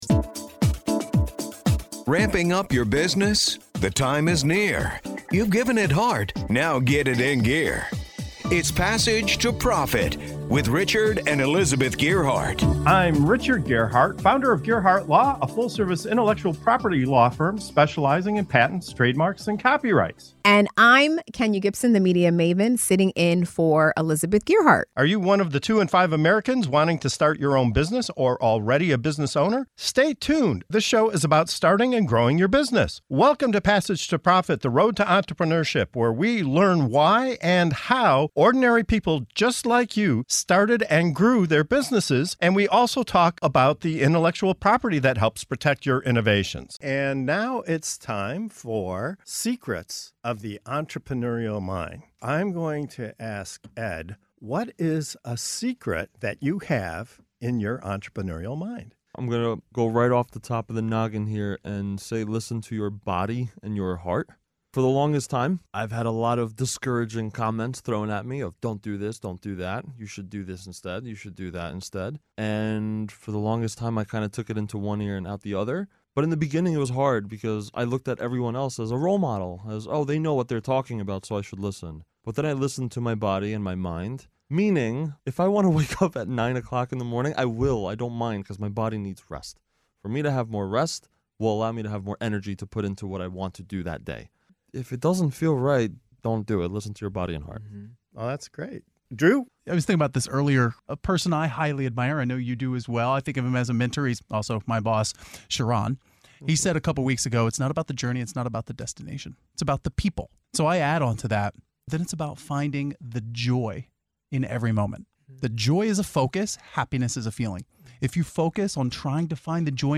In this segment of "Secrets of the Entrepreneurial Mind" on the Passage to Profit Show our guests share powerful insights on finding joy in every moment, listening to your body and heart, embracing gratitude, and crafting morning routines that set the tone for the day. Whether you're an early riser or a night owl, this conversation reveals how to harness your inner strengths and make every day a step toward achieving your dreams.